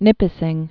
(nĭpĭ-sĭng), Lake